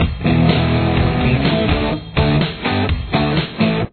Verse
Here’s what the guitar and bass sound like together: